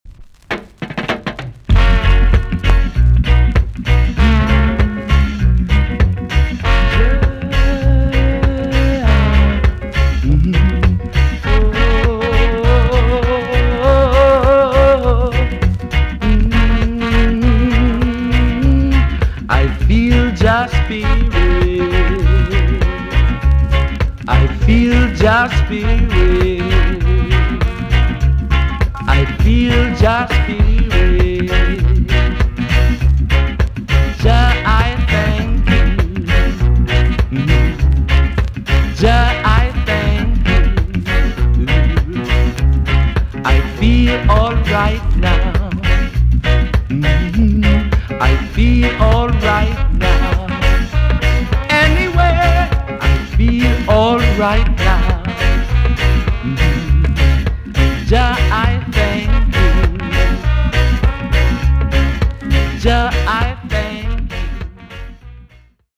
TOP >REGGAE & ROOTS
EX-~VG+ 少し軽いチリノイズがありますが良好です。